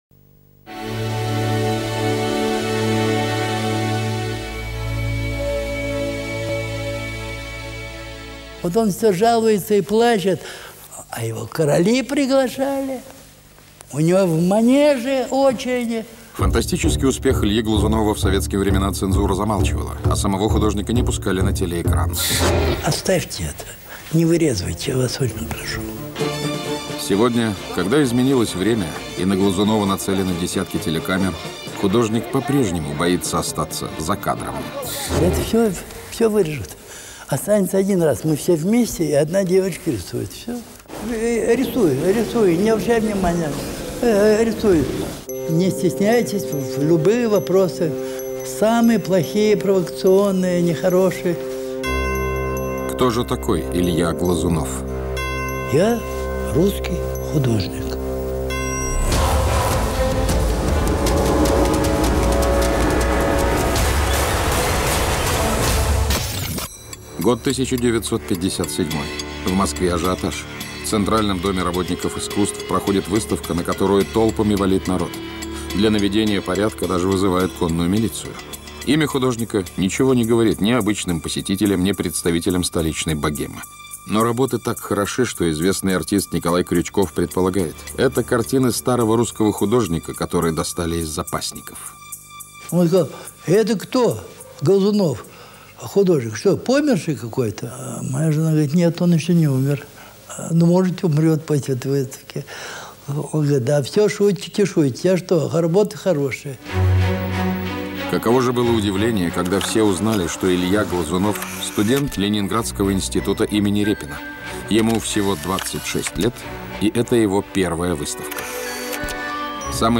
Подкасты Наши светочи Документальный фильм «Илья Глазунов. Вопреки» В семидесятые годы Глазунов казался властям слишком «русским», теперь многим русским людям он кажется слишком близким к властям.